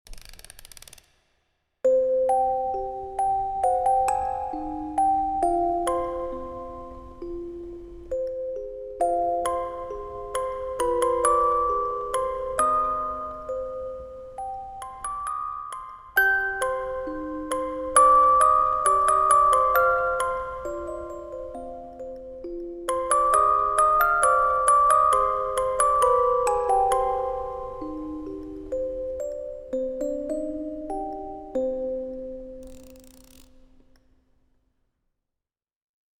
красивые
мелодичные
спокойные
без слов
пугающие
звонкие
Melodic
Музыкальная шкатулка